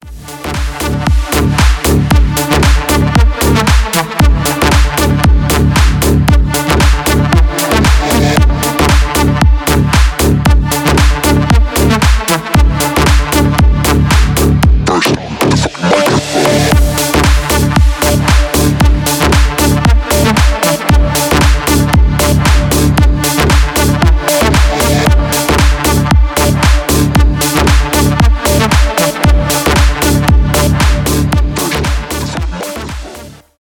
edm , club house